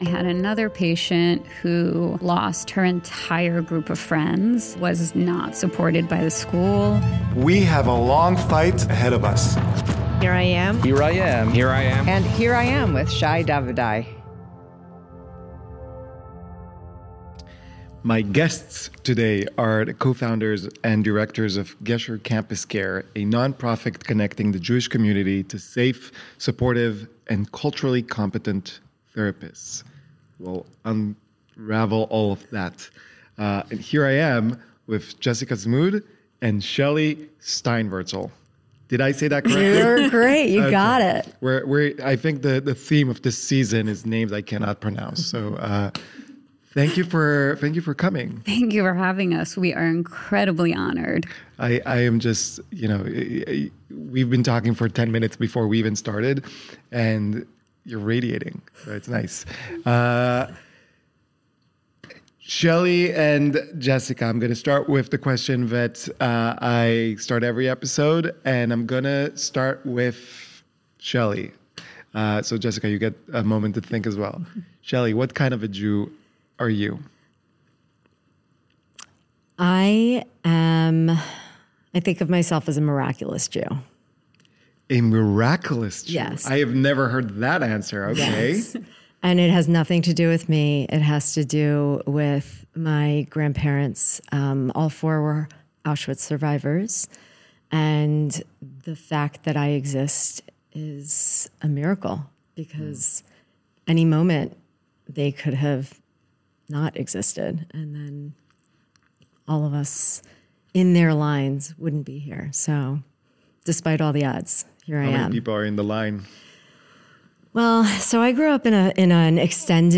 The conversation highlights the importance of Jewish pride, resilience, and community, and offers hope for a future where everyone can express their identity without fear.